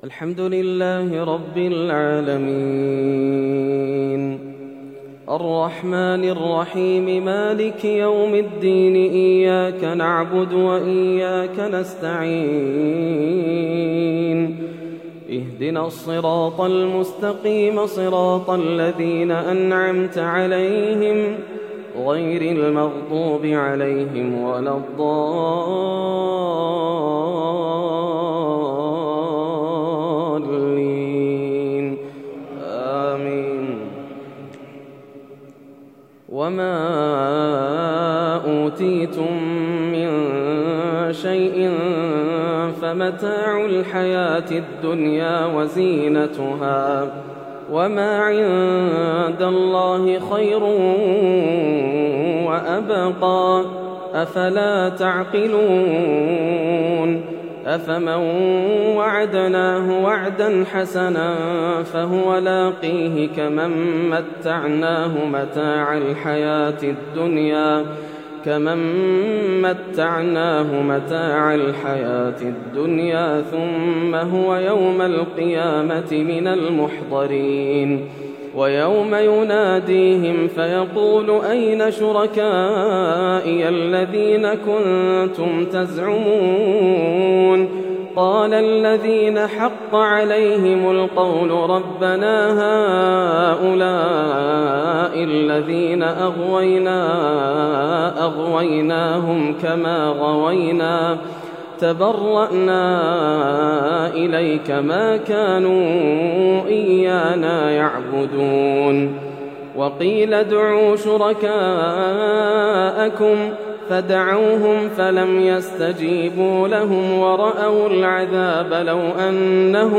صلاة التراويح من سورة القصص حتى سورة الأحزاب للشيخ ياسر الدوسري | ليلة ٢٤ رمضان ١٤٣١هـ > رمضان 1431هـ > مزامير الفرقان > المزيد - تلاوات الحرمين